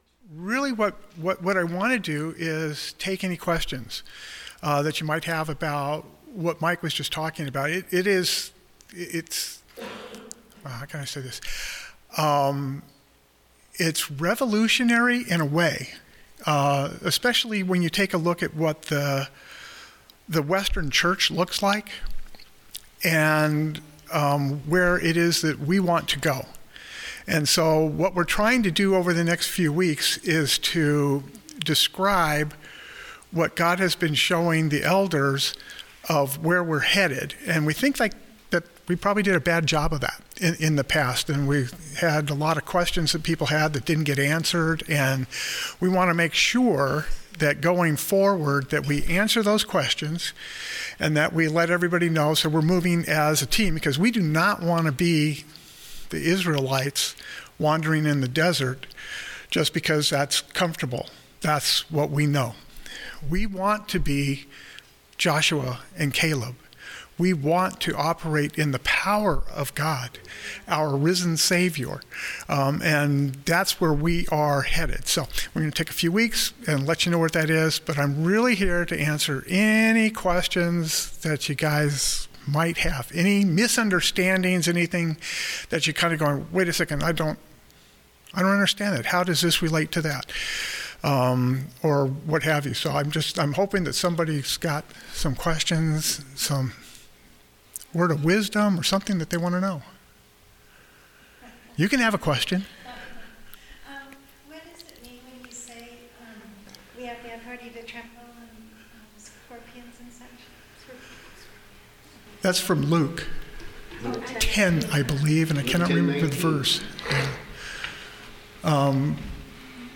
PREPARING TO ENTER THE PROMISED LAND All Sermons